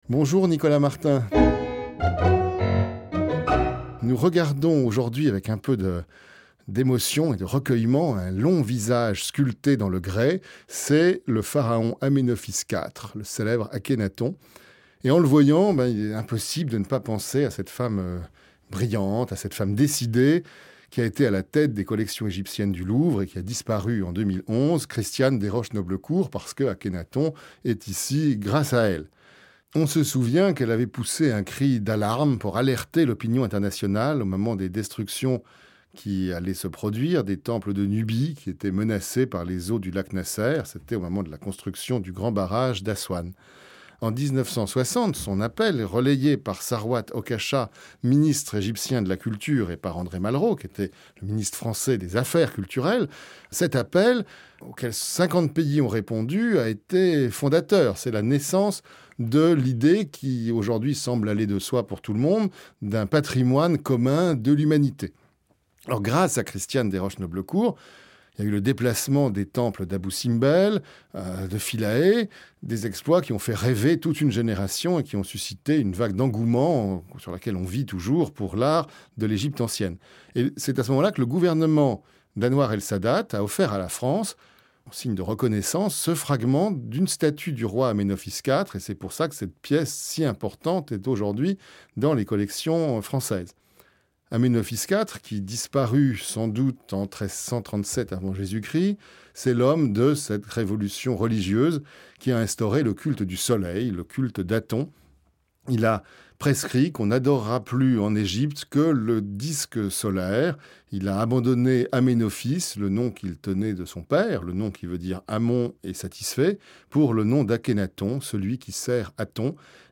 Émission de radio